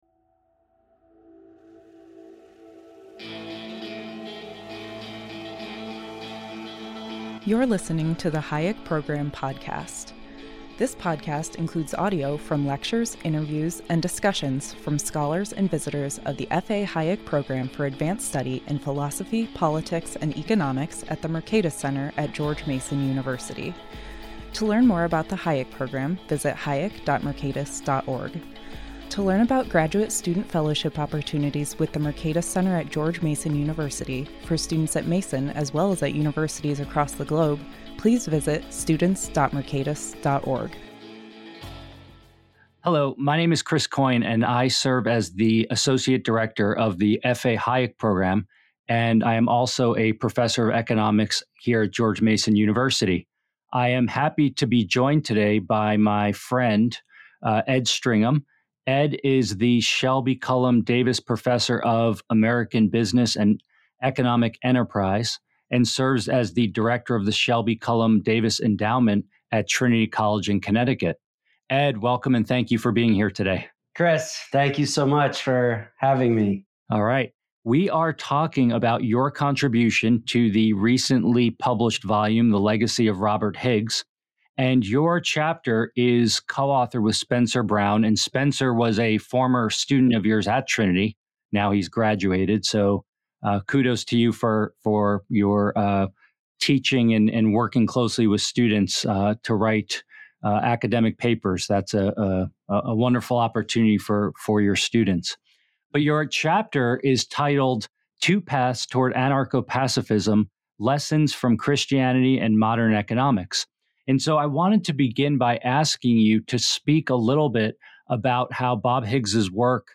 The first four episodes of this series will focus on The Legacy of Robert Higgs (Mercatus Center, 2024) and will feature a collection of short interviews with many of the chapter authors. This episode focuses on state power, peaceful cooperation, and the regime uncertainty of…